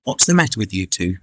Text-to-Speech
add clones